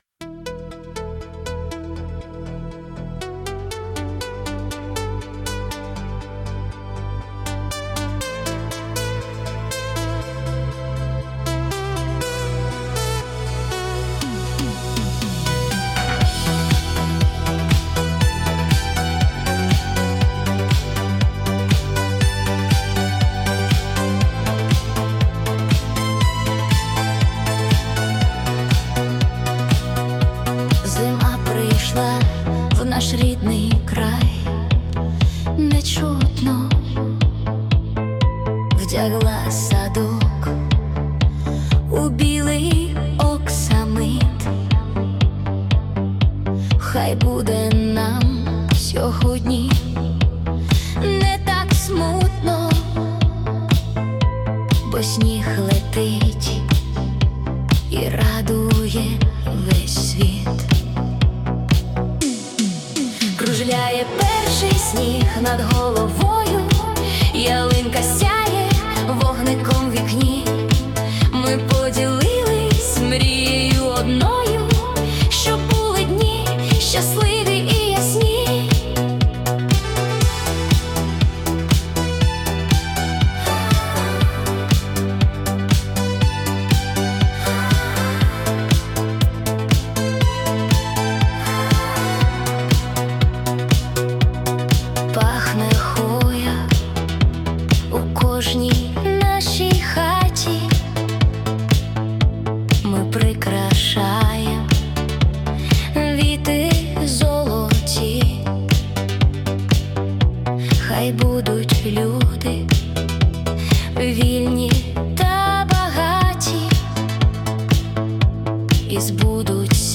🎵 Жанр: Italo Disco / Dance